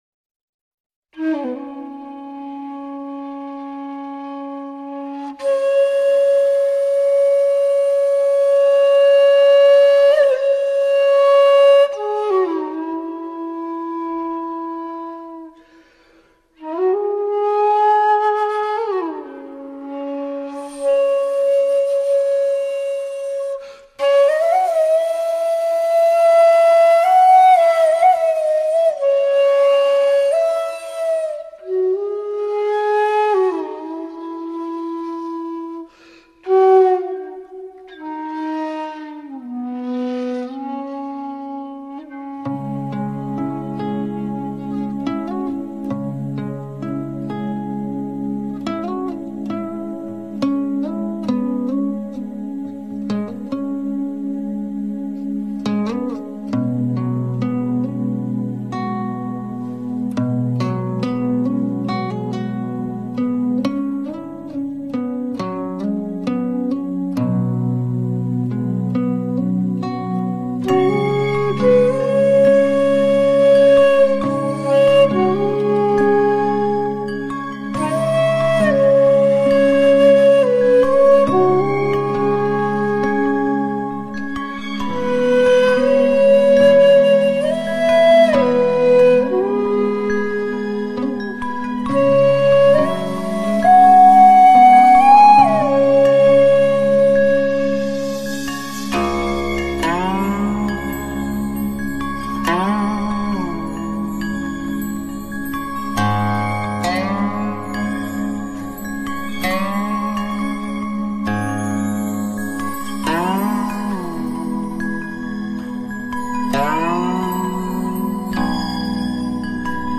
3周前 纯音乐 7